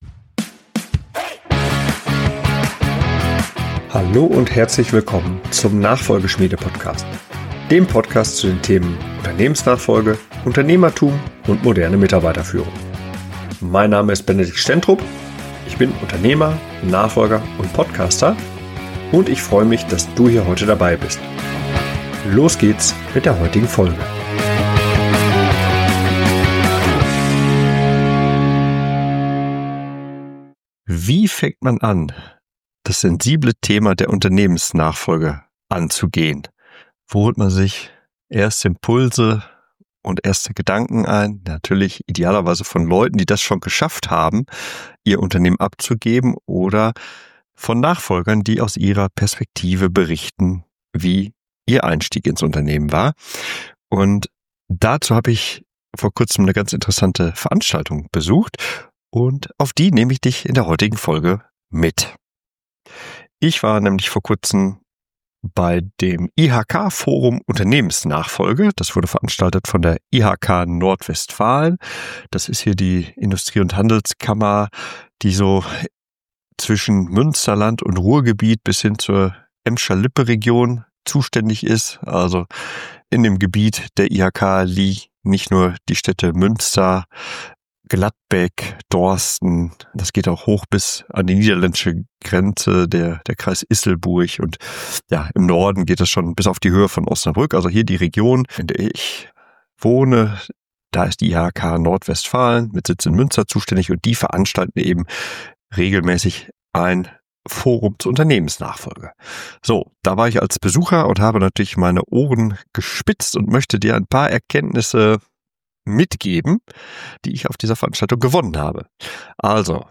Beschreibung vor 10 Monaten In der neuesten Folge des Nachfolgeschmiede-Podcasts nehme ich Euch mit auf eine Veranstaltung: Ich habe das IHK Nachfolgeforum der IHK Nord-Westfalen besucht. An einem Nachmittag drehte sich im westfälischen Dorsten alles um die Bedeutung der Unternehmensnachfolge.